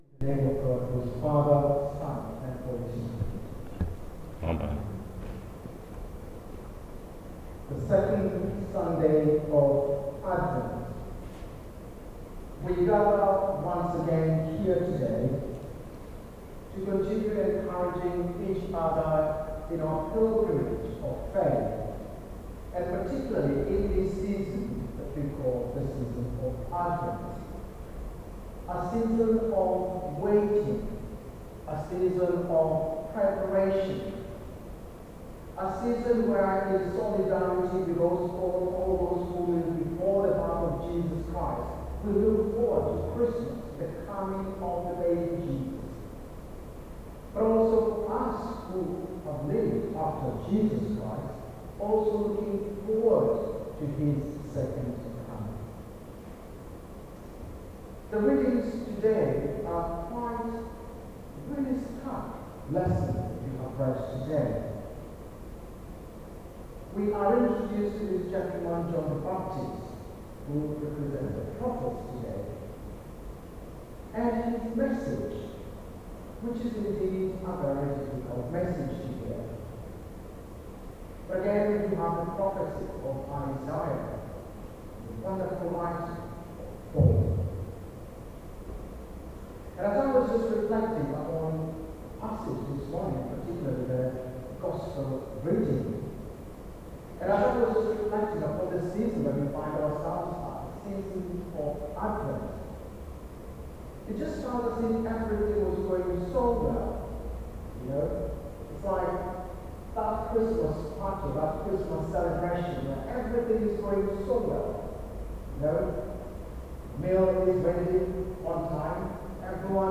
Sermon: The Spirit and the Winnowing Fork | St Paul + St Stephen Gloucester